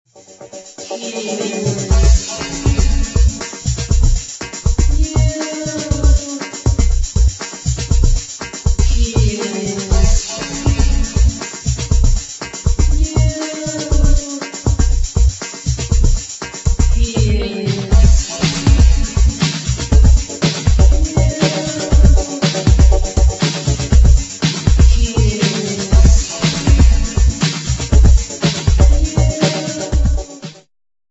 elektronische Musik